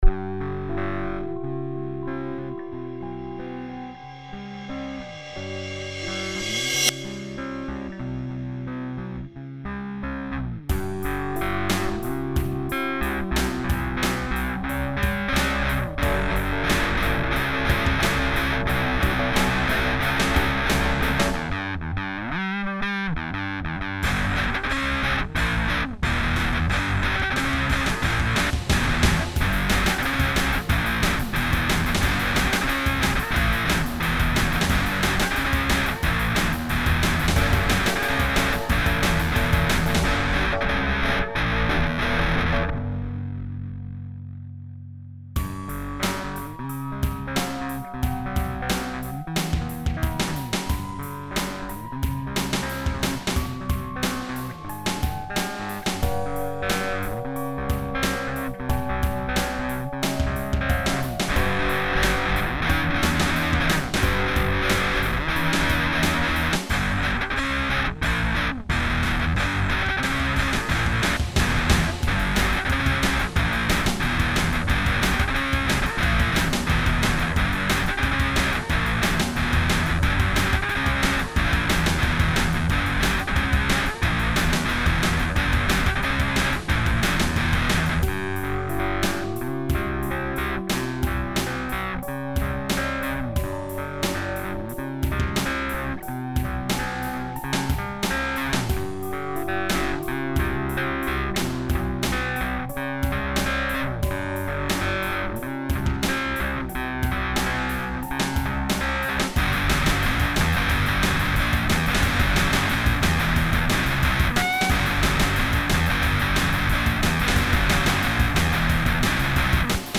musique electropop